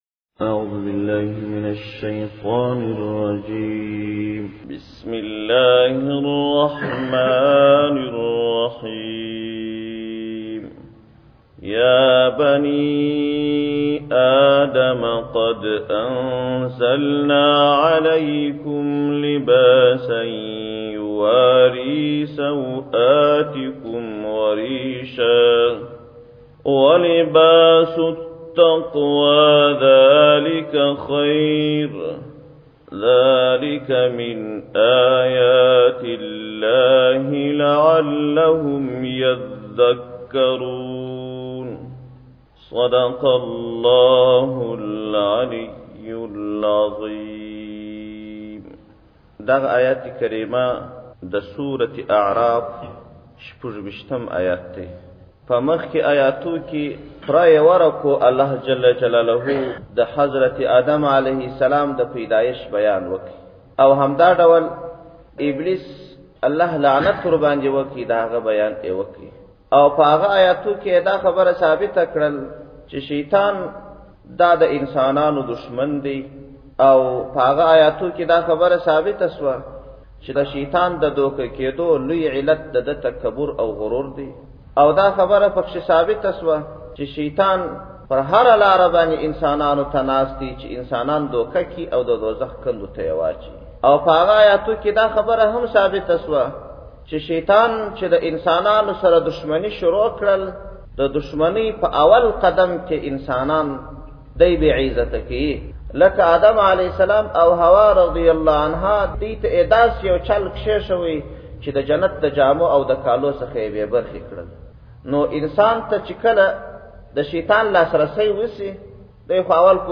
جنوري 17, 2017 تفسیرشریف, ږغیز تفسیر شریف 1,219 لیدنی